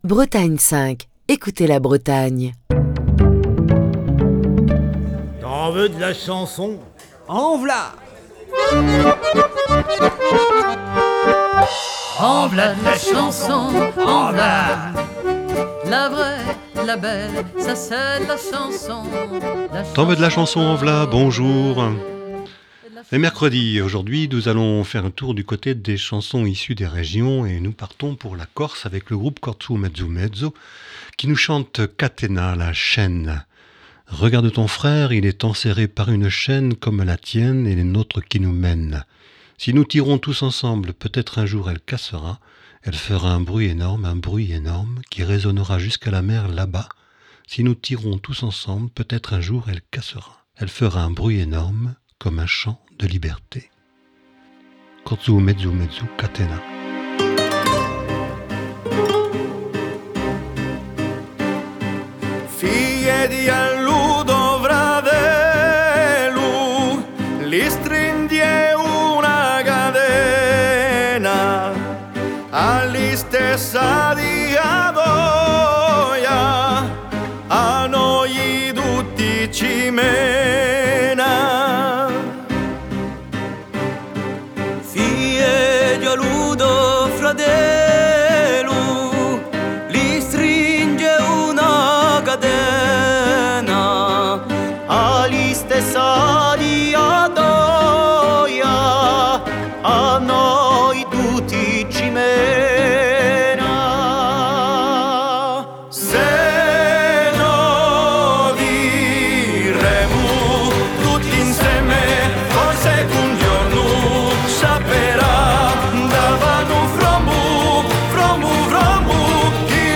Chronique du 5 avril 2023.